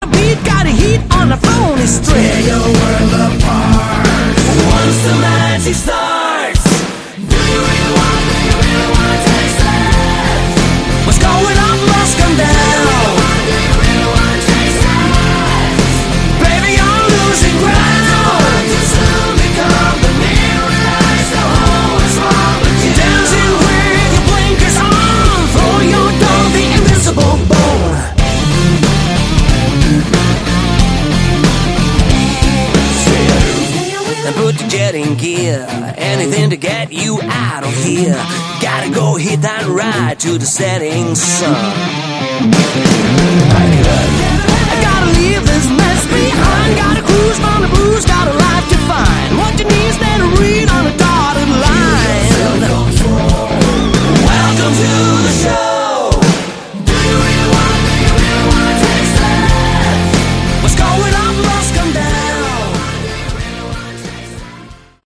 Category: Hard Rock
Glam vocals
Teeny guitars
Flash bass
Sporty drums